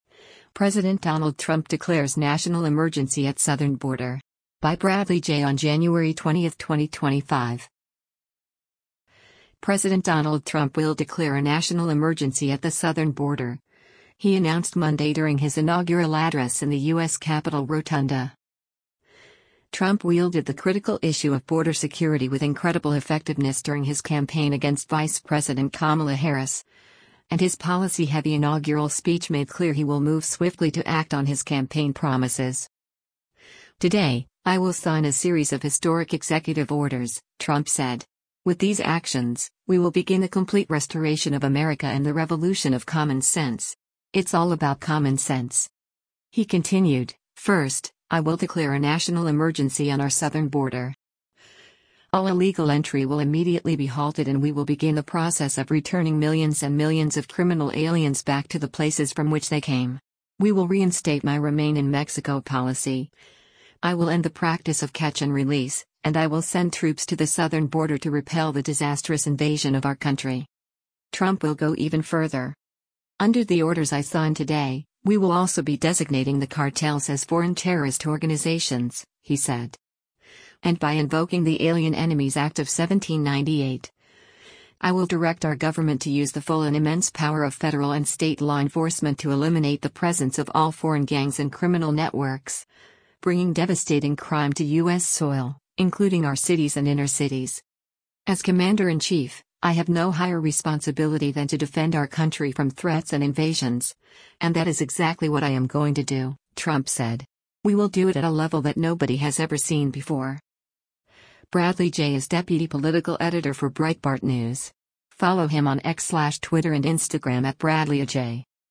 President Donald Trump will declare a national emergency at the southern border, he announced Monday during his inaugural address in the U.S. Capitol rotunda.